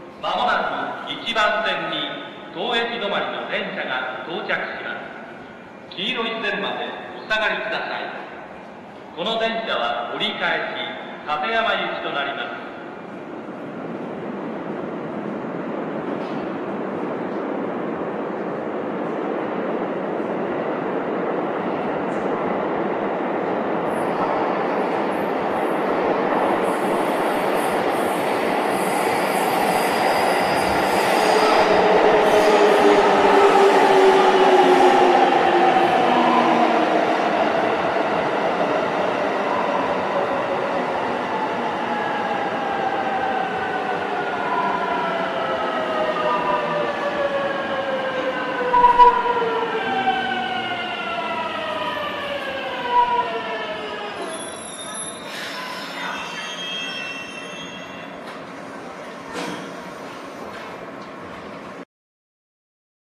なぜか変調する度に異音が入る編成が多いです。
減速音（ビューさざなみ14号）
収録区間：京葉線 東京（ホーム上で収録）